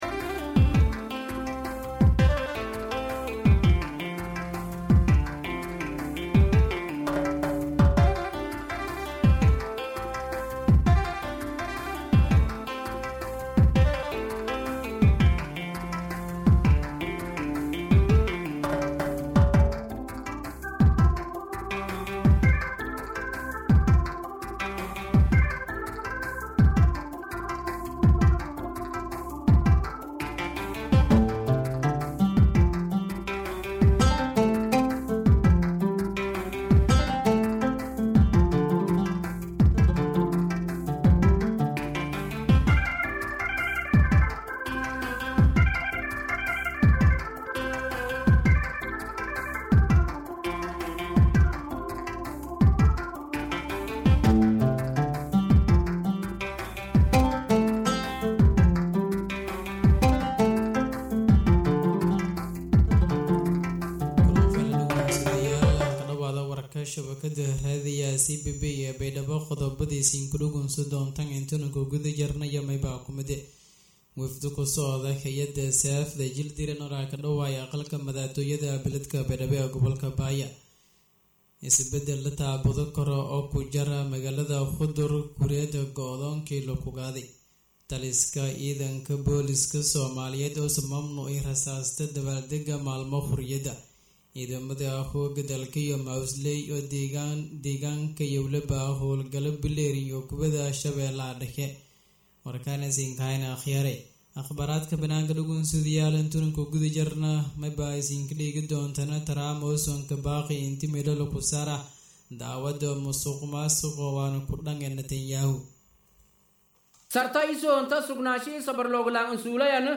{DHAGEYSO} Warka Duhurnimo ee Warbaahinta Radio Codka Baay Iyo Bakool {26.6.2025}